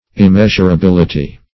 Meaning of immeasurability. immeasurability synonyms, pronunciation, spelling and more from Free Dictionary.